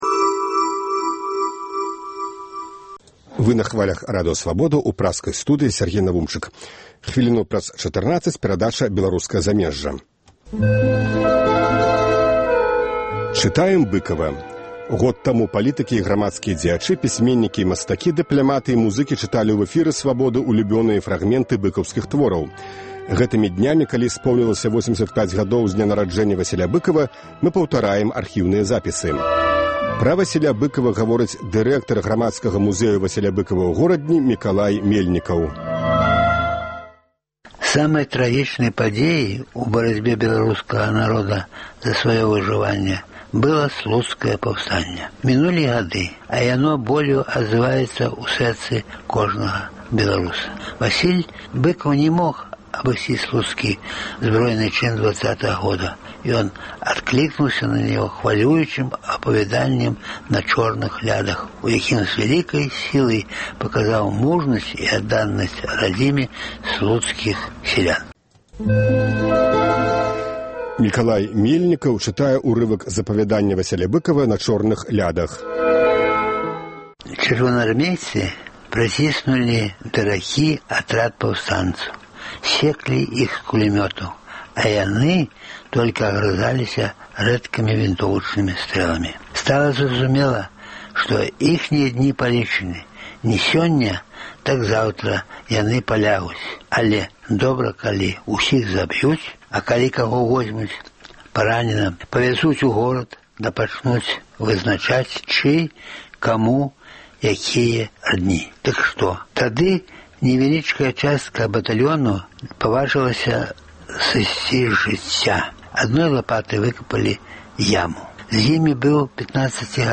Вядомыя людзі Беларусі чытаюць свае ўлюбёныя творы Васіля Быкава.
Былы амбасадар Ізраілю ў Беларусі Зэеў Бэн-Ар'е чытае фрагмэнт з апавяданьня "Бедныя людзі".